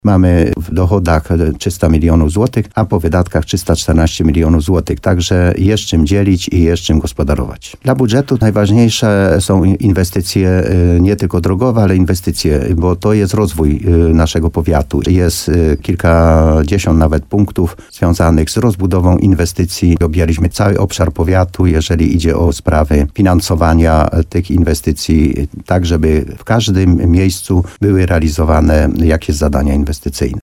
Jak mówił w programie Słowo za Słowo w radiu RDN Nowy Sącz wicestarosta nowosądecki Antoni Koszyk, budżet na nowy rok będzie ponad 10% większy od tegorocznego.